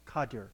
In computer programming, CAR (car) /kɑːr/ and CDR (cdr) (/ˈkʌdər/
US-Lisp-CDR2.ogg.mp3